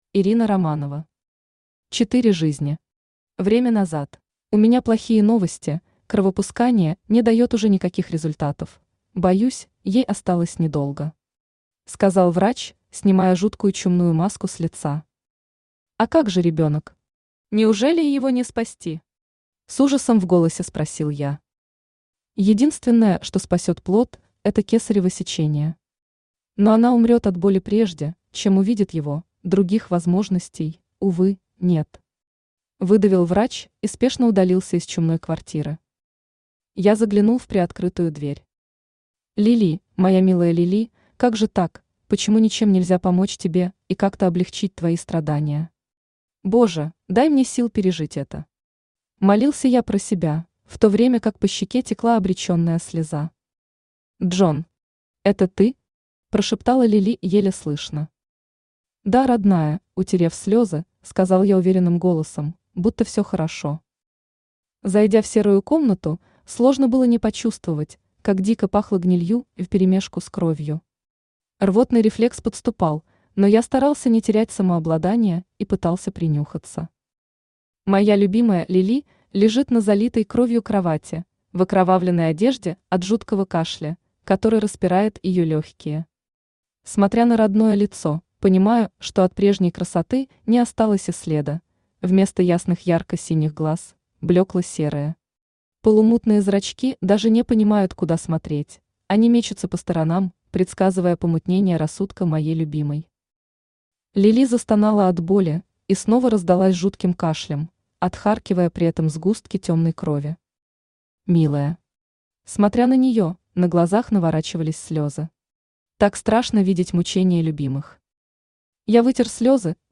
Аудиокнига Четыре жизни. Время назад | Библиотека аудиокниг
Время назад Автор Ирина Романова Читает аудиокнигу Авточтец ЛитРес.